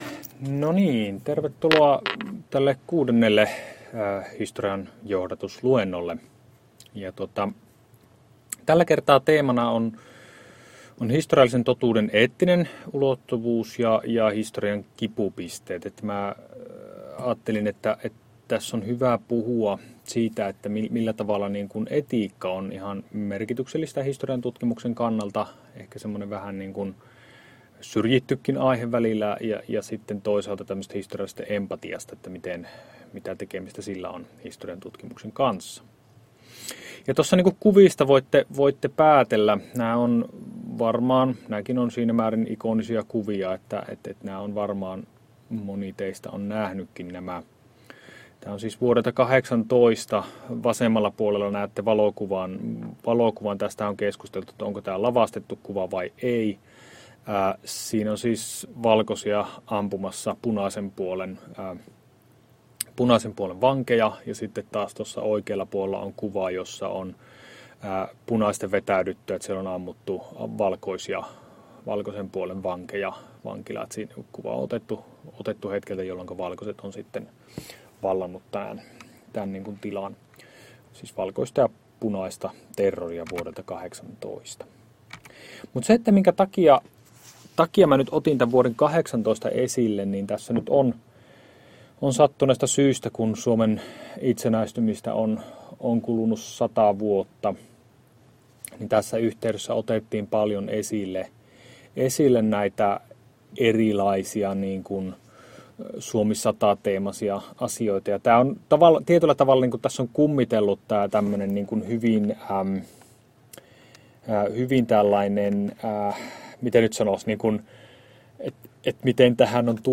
Luento 6 — Moniviestin